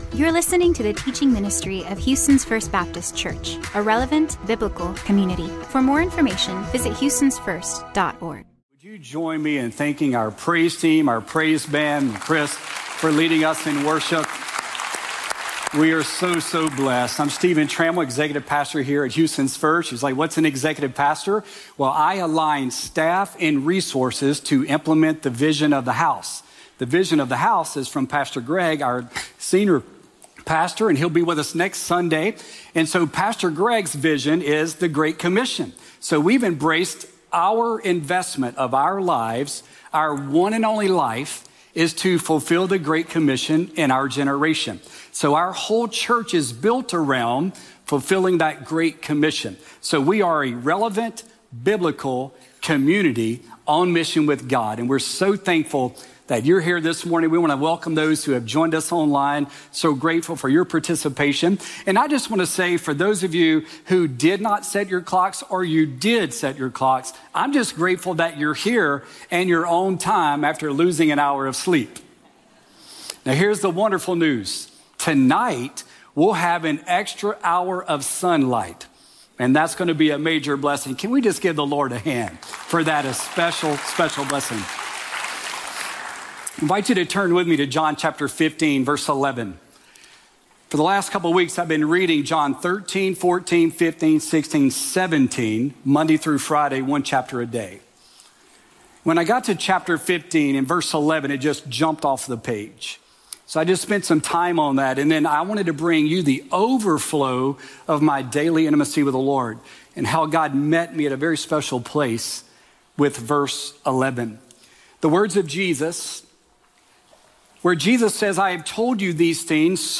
This podcast provides weekly messages